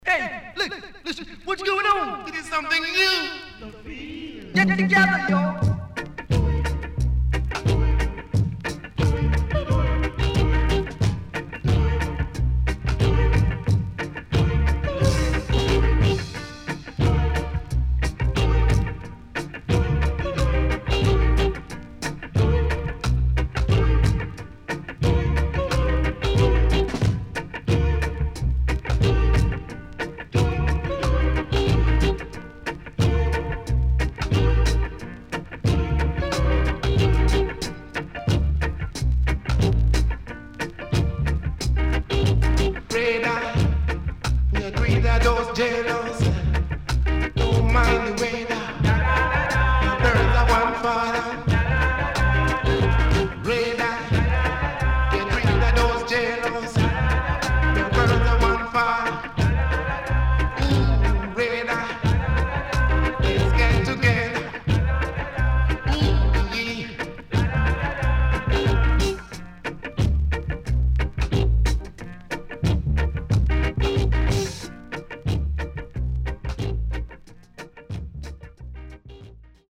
HOME > Back Order [VINTAGE 7inch]  >  EARLY REGGAE
SIDE A:少しチリノイズ入ります。